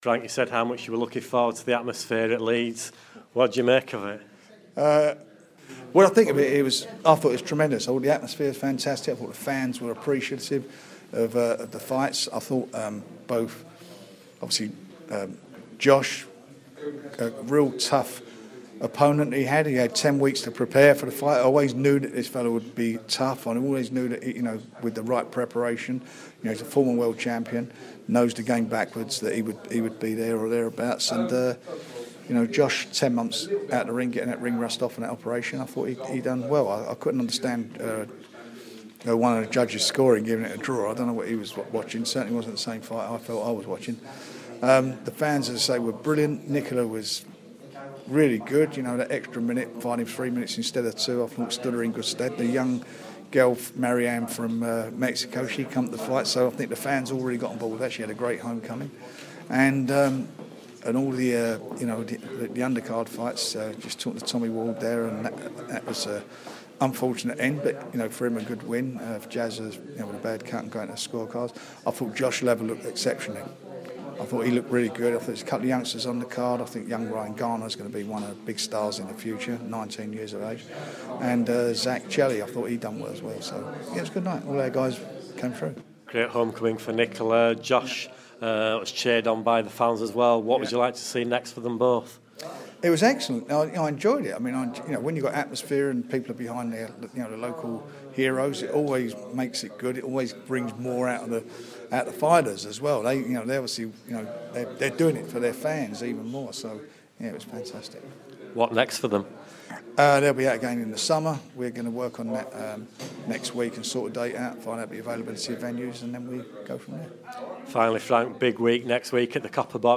Frank Warren speaks to Radio Yorkshire after a night of boxing at the First Direct Arena in Leeds.